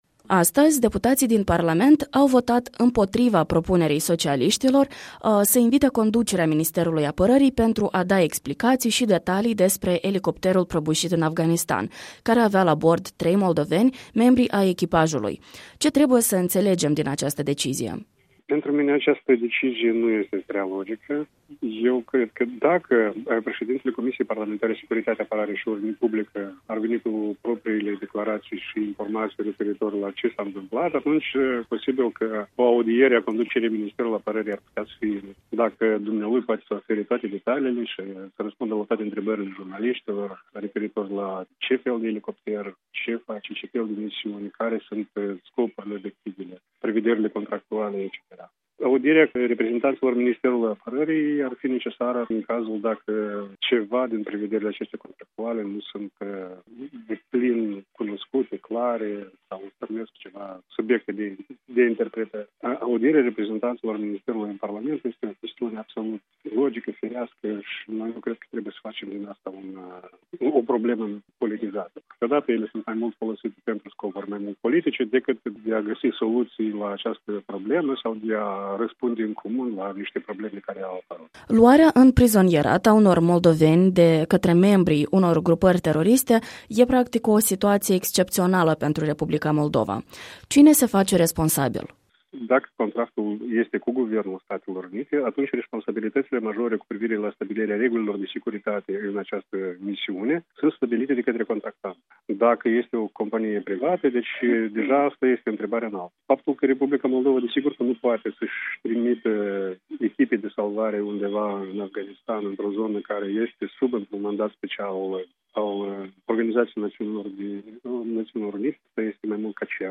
O discuție